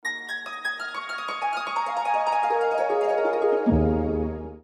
• Качество: 320, Stereo
красивые
без слов
струнные
инструментальные
арфа
Короткая мелодия на арфе